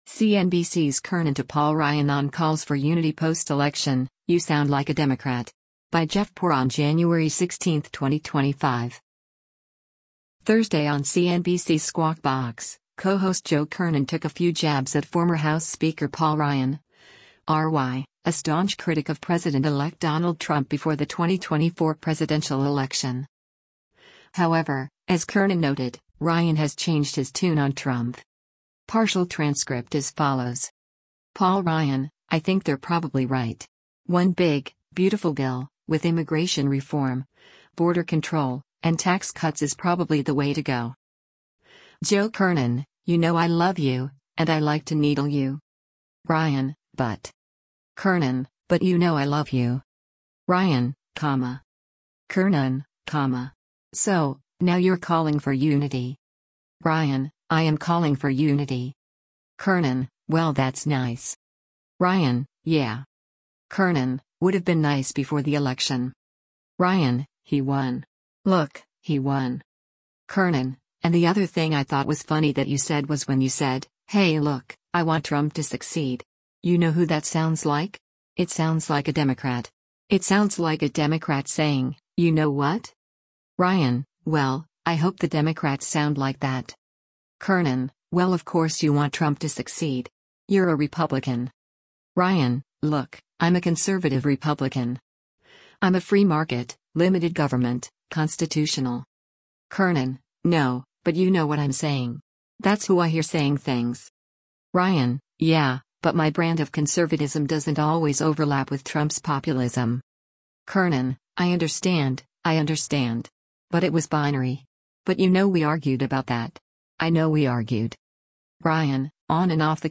Thursday on CNBC’s “Squawk Box,” co-host Joe Kernen took a few jabs at former House Speaker Paul Ryan (R-WI), a staunch critic of President-elect Donald Trump before the 2024 presidential election.